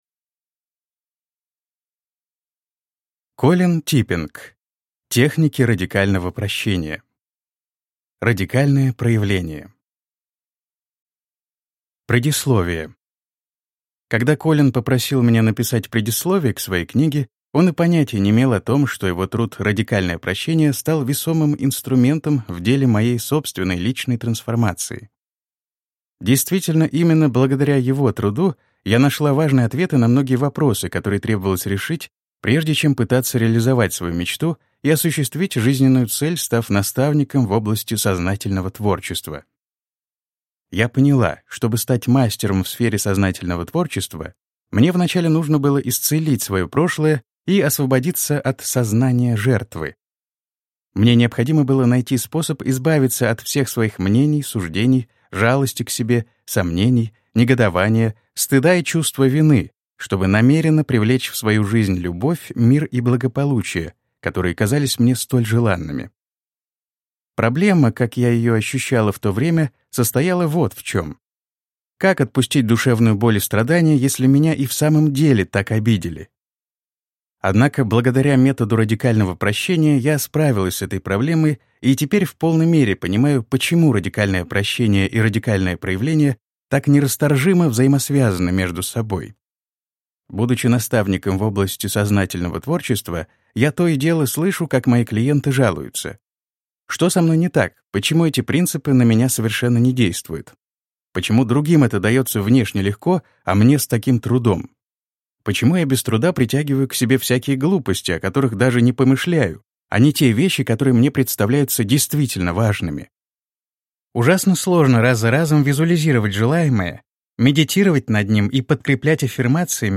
Аудиокнига Техники Радикального Прощения: Радикальное Проявление | Библиотека аудиокниг